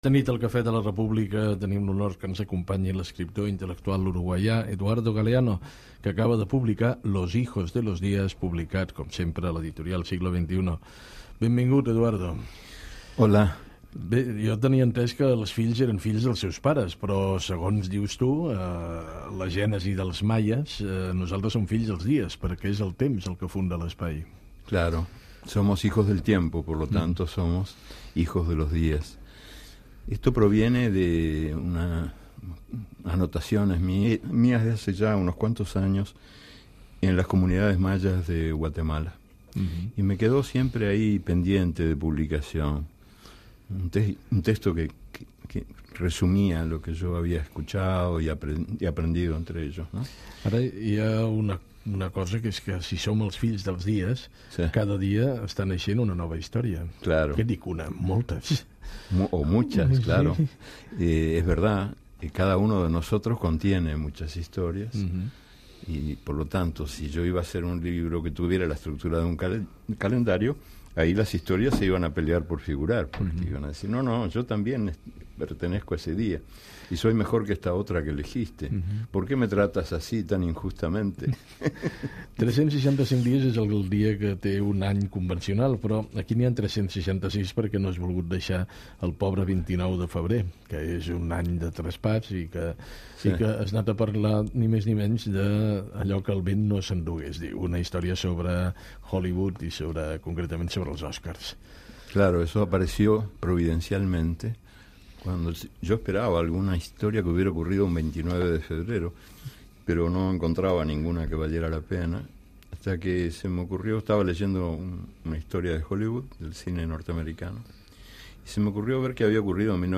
Presentació i entrevista a l'escriptor Eduardo Galeano que presenta el llibre "Los hijos de los dias"
Info-entreteniment